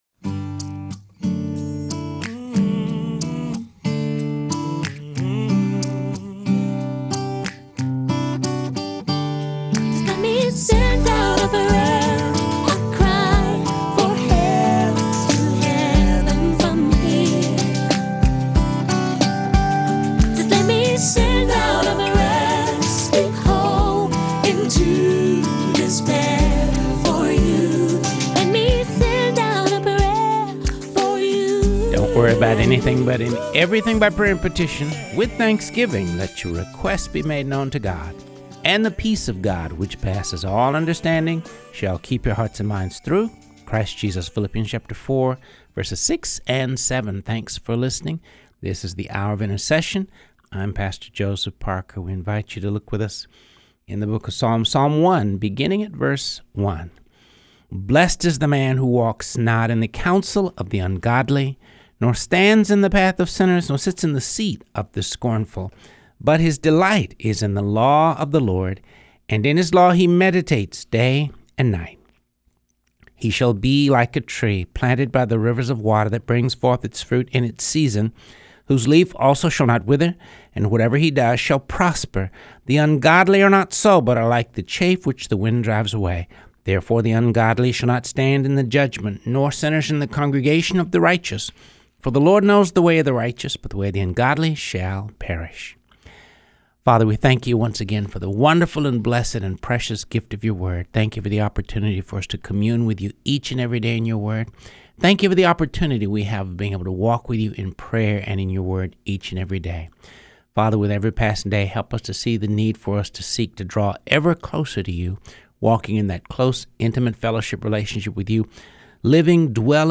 reading through the Bible